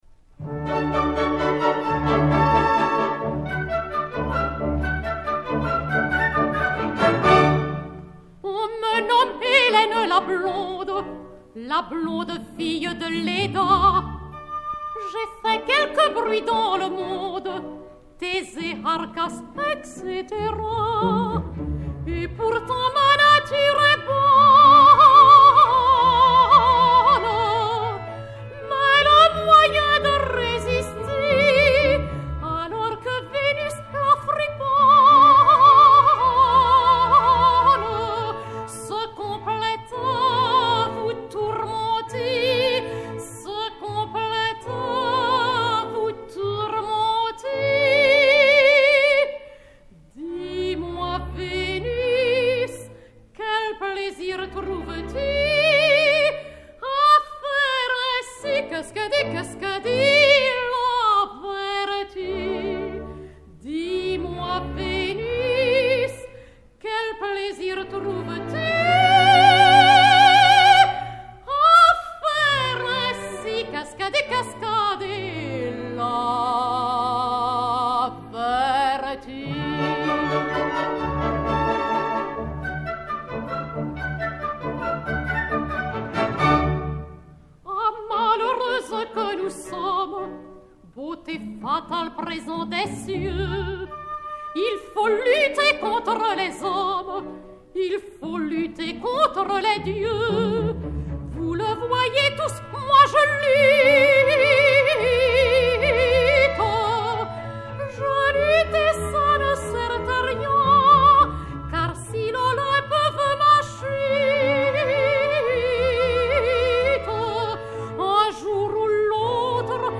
45 tours Orphée, enr. en 1964